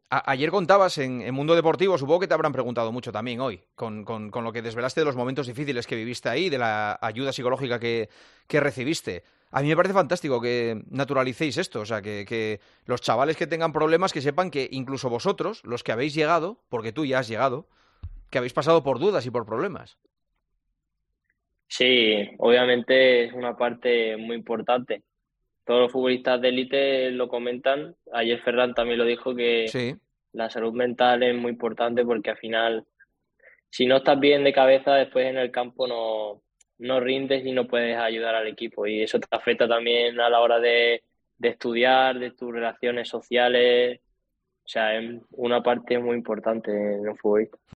Fermín López se suma a la lista de futbolistas que hacen público haber tenido que pedir ayuda para resolver problemas psicológicos. Y lo reivindicó en El Partidazo de COPE.